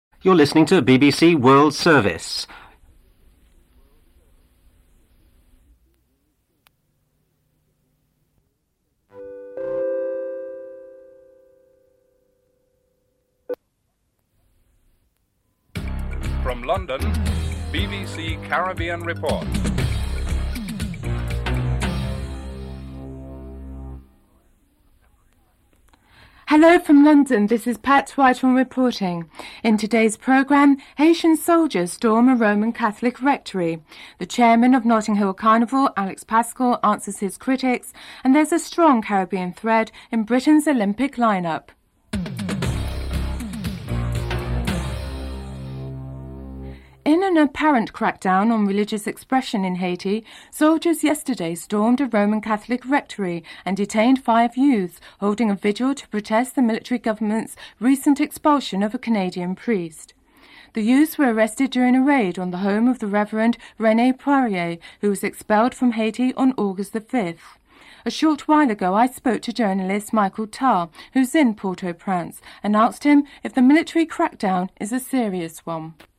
6. This segment features comments and complaints from West Indies supporters regarding what they perceive as discriminatory acts towards them by cricket clubs.